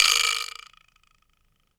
wood_vibraslap_hit_02.wav